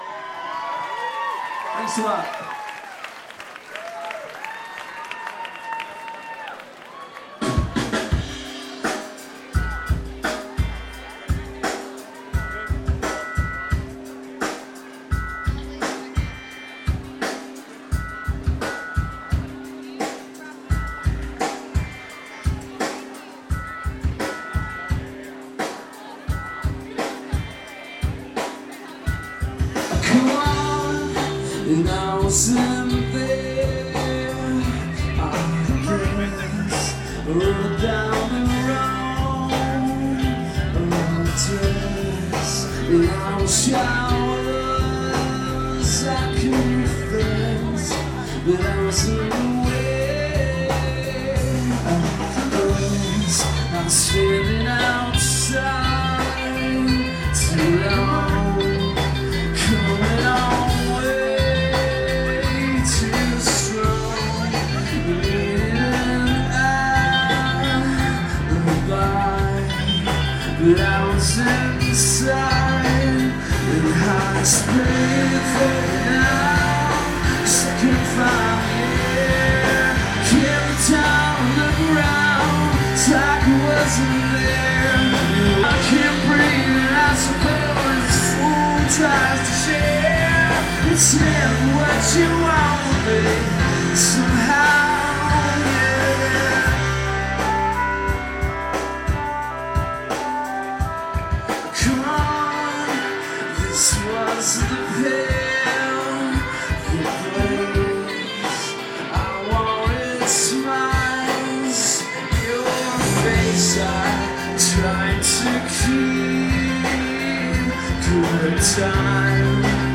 new york city march 3 2003